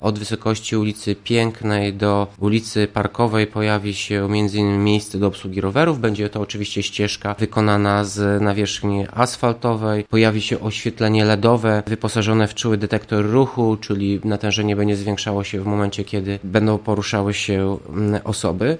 O szczegółach mówi Tomasz Andrukiewicz , prezydent Ełku: